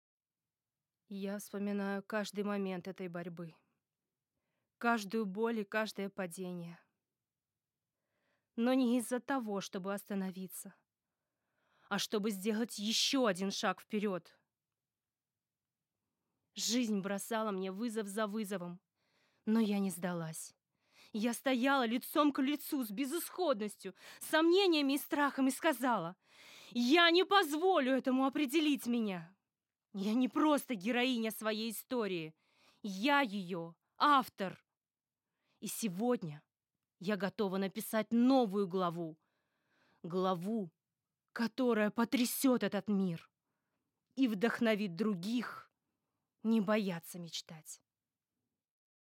Монолог